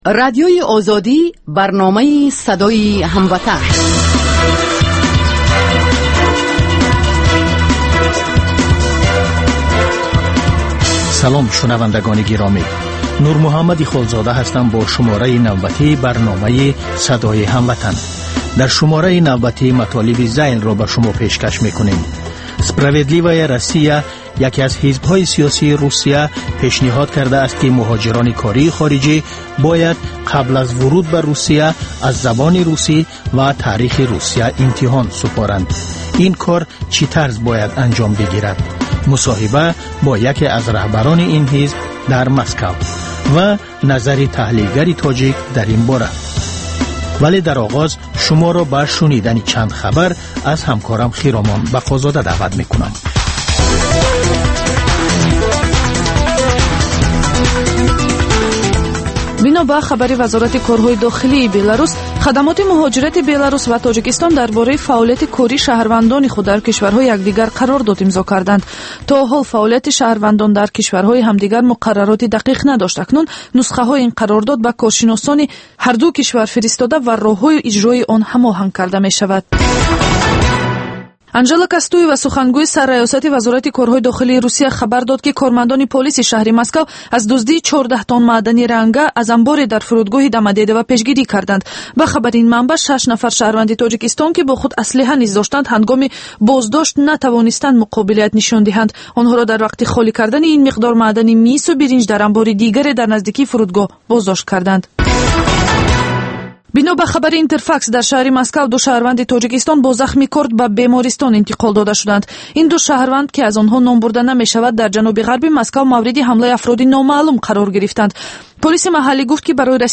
Баррасии рӯйдодҳои сиёсии Тоҷикистон, минтақа ва ҷаҳон дар гуфтугӯ бо таҳлилгарон.